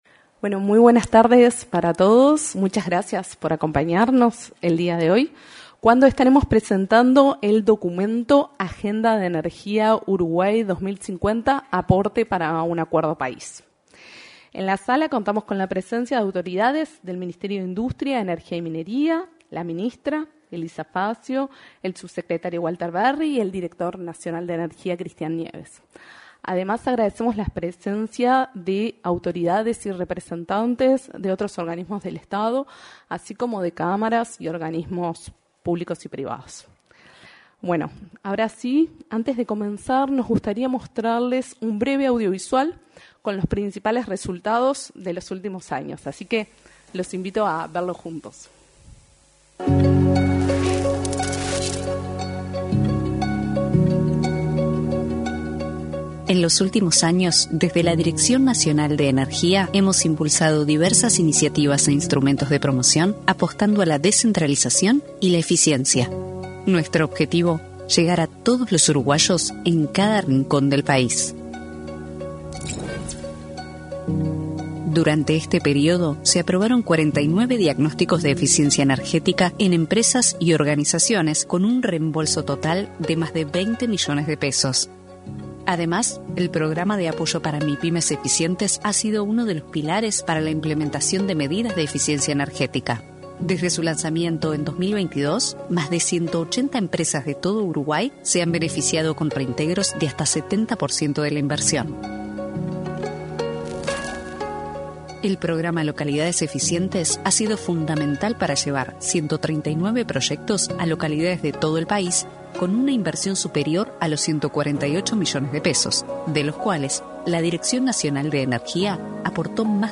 En la oportunidad, se expresaron el director nacional de Energía, Christian Nieves; el subsecretario del Ministerio de Industria, Energía y Minería, Walter Verri, y la titular de la citada cartera, Elisa Facio.